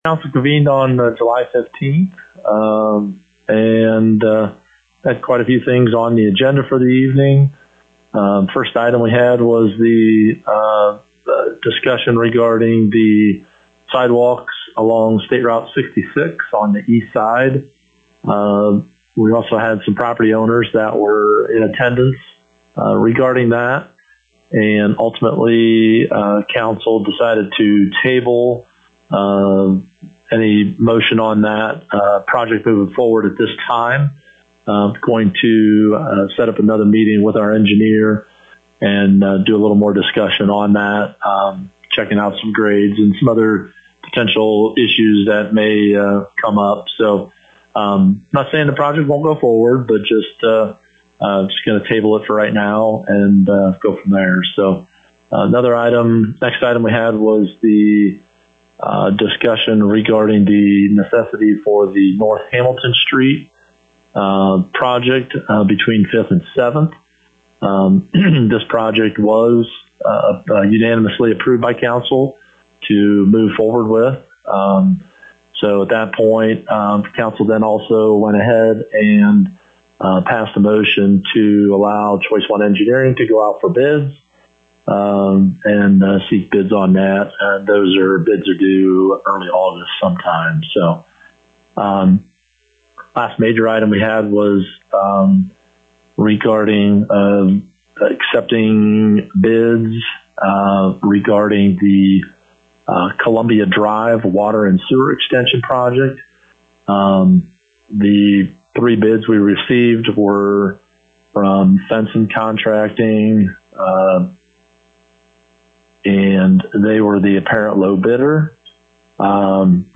For a summary with Minster Mayor Craig Oldiges: